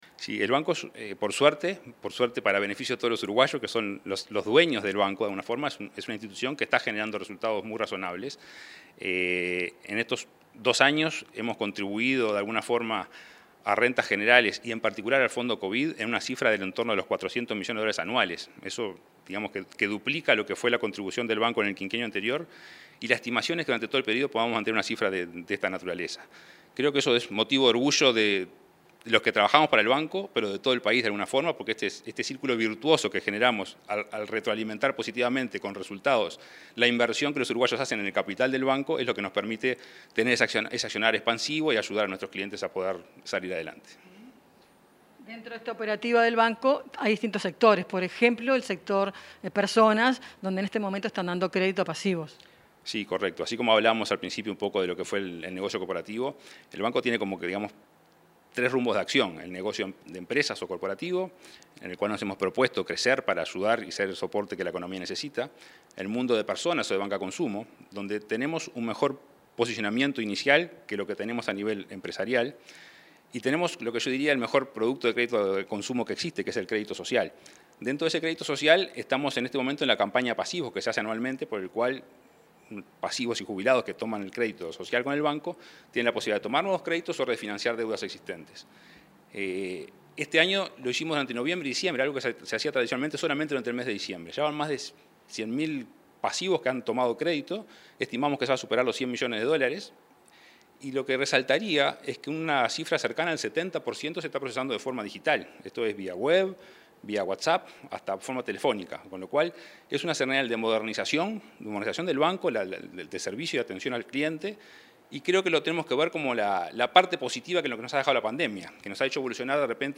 Entrevista al presidente del BROU, Salvador Ferrer